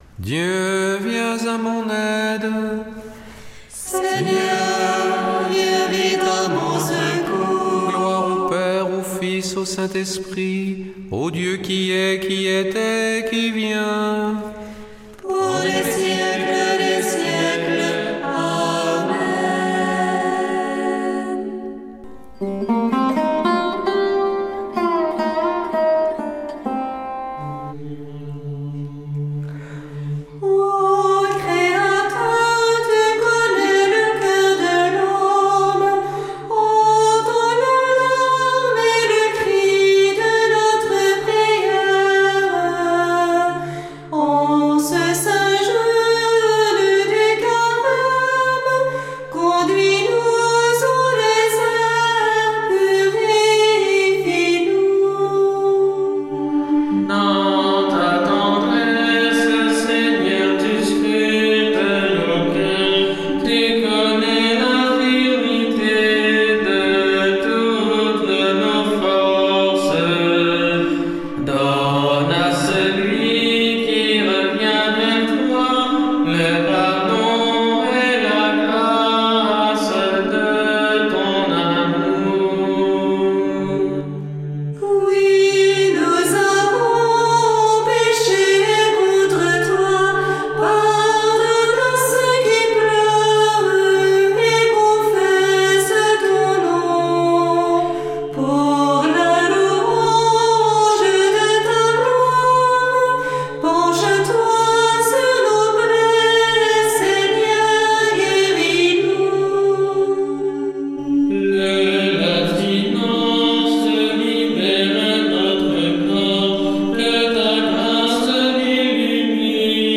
Prière des complies - Temps de carême